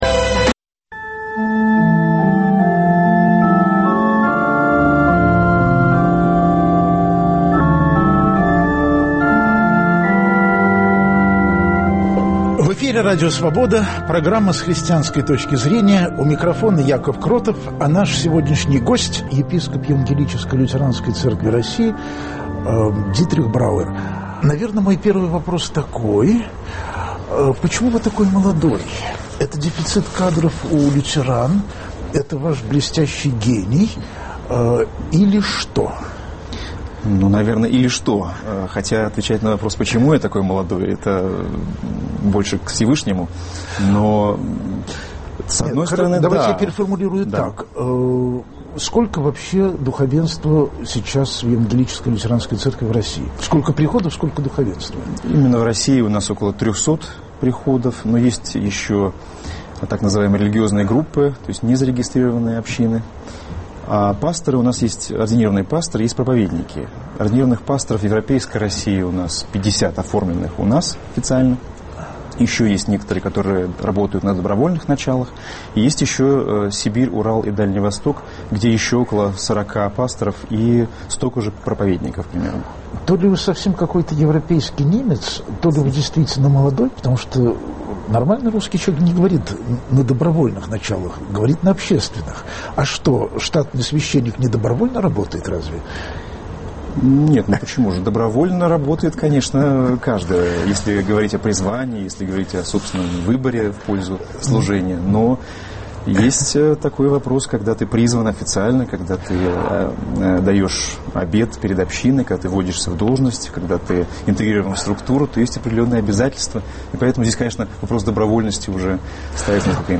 Каково лютеранам в православной России и в Москве с её старыми антинемецкими фобиями? Об этом в программе разговор с епископом Евангелическо-Лютеранской Церкви России Дитрихом Брауэром.